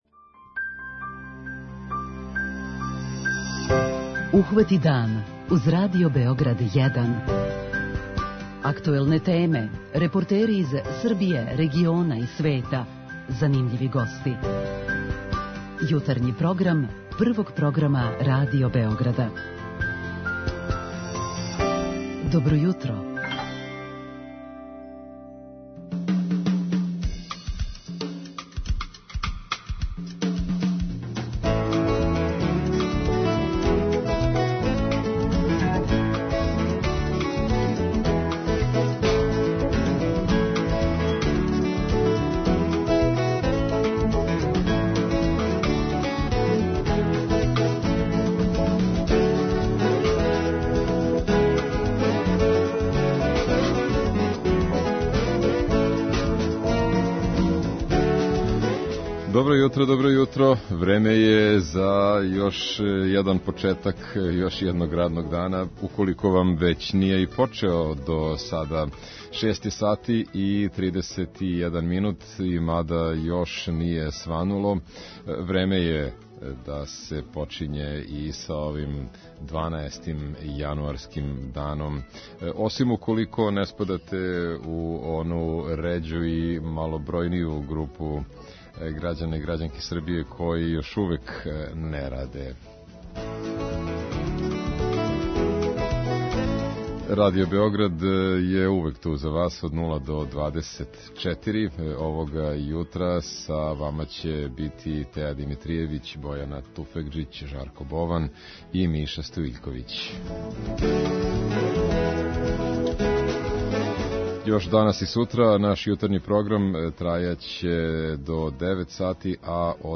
Од како је Немачка најавила да ће у првом кварталу ове године донети прописе који ће додатно олакшати увоз страних радника, посебно с Балкана, медији у Србији готово свакодневно се баве овом темом. Због тога ћемо у јутарњем програму питати наше слушаоце шта би требало урадити да се домаћа привреда заштити од потенцијалног губитка радне снаге.
После хоспитализације шесторице рудара из Штавља због сумњи на тровање, чућемо се с нашим дописницима из Санџака и од њих добити најсвежије информације. Сазнаћемо и шта кажу узгајивачи воћа о томе како ће неуобичајено топло време ове зиме утицати на засаде воћки.